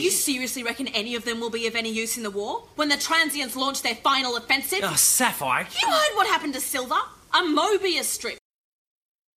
Audio drama
The new individuals speak with what seem to be Australian accents instead of British and the pair seem to have transposed personalities, with Sapphire being impatient, stern, and often rude and Steel being more kind-hearted; but they each seem to have the same individual abilities.
Memorable Dialog